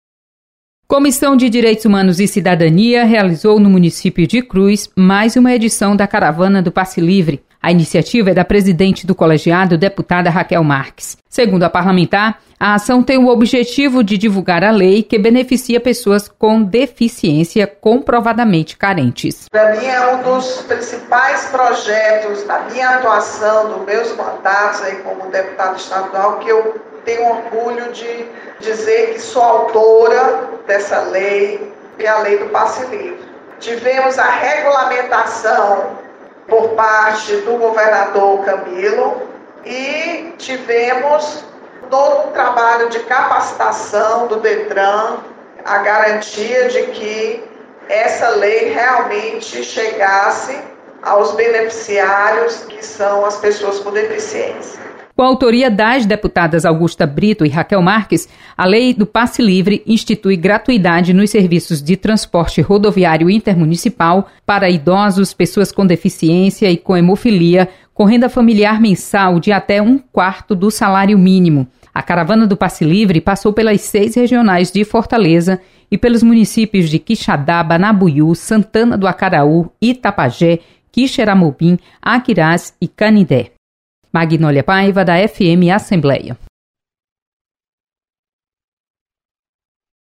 Caravana divulga o direito à lei do Passe Livre. Repórter